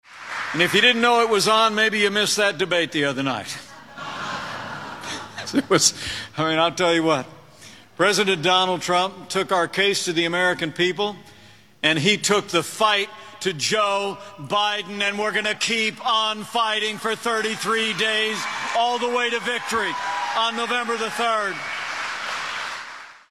During a speech early this (Thursday) afternoon in western Iowa, Pence began by praising Trump’s performance in Tuesday night’s debate.